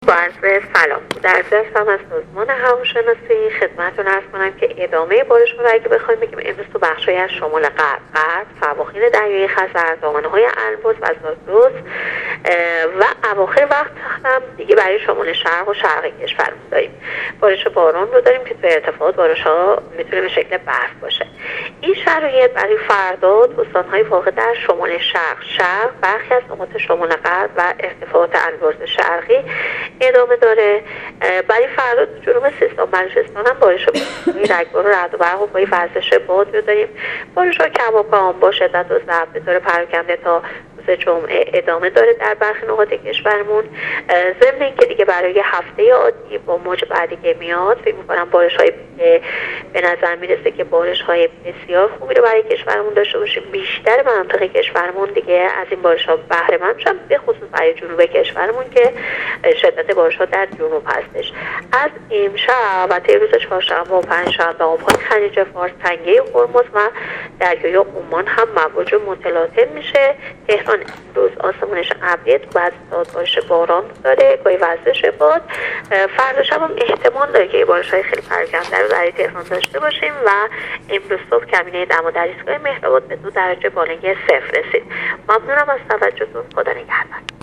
گزارش رادیو اینترنتی از آخرین وضعیت آب و هوای دهم دی ۱۳۹۸